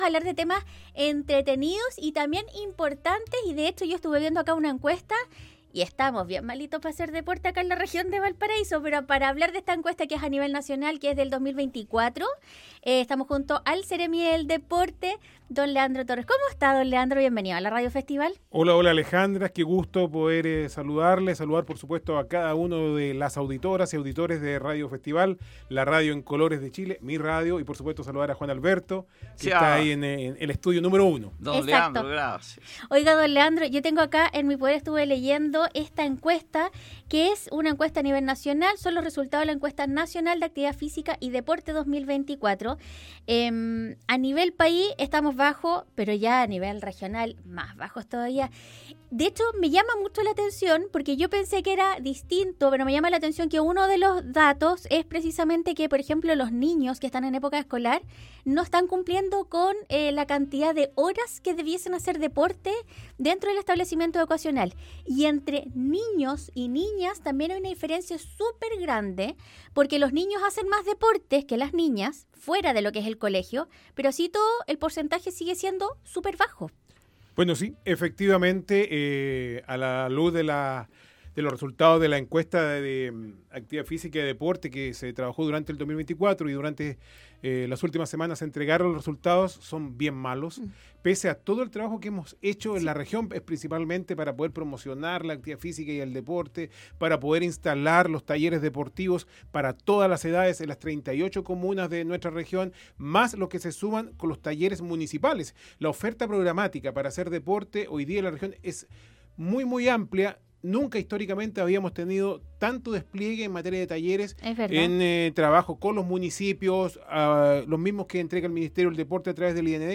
El seremi del Deporte Leandro Torres conversó con Radio Festival sobre los índices de actividad física en la zona.